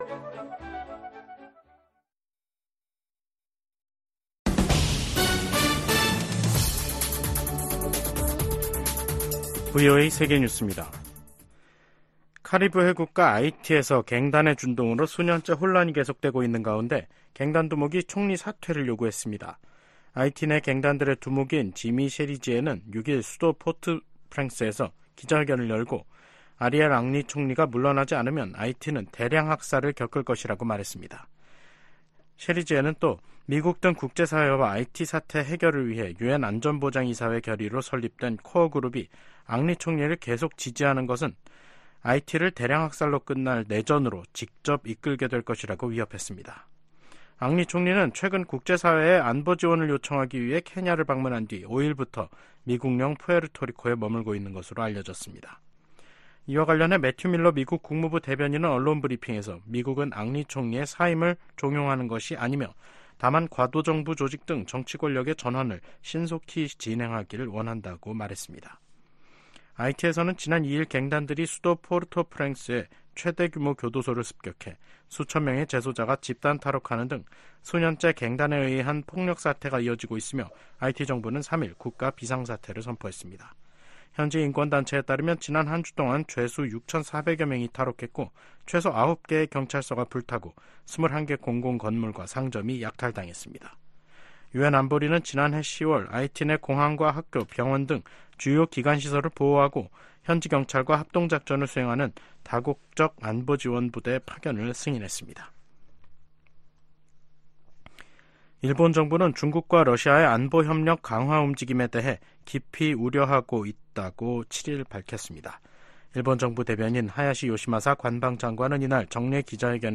VOA 한국어 간판 뉴스 프로그램 '뉴스 투데이', 2024년 3월 7일 3부 방송입니다. 김정은 북한 국무위원장이 서부지구 작전훈련 기지를 방문해 전쟁준비 완성과 실전훈련 강화를 강조했다고 관영 매체들이 보도했습니다. 미국 정부는 현재 진행 중인 미한 연합훈련이 전쟁연습이라는 북한의 주장을 일축했습니다. 미국이 국제원자력기구(IAEA) 이사회에서 북한-러시아 탄도미사일 거래를 강력 규탄했습니다.